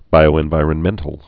(bīō-ĕn-vīrĕn-mĕntl, -vīərn-)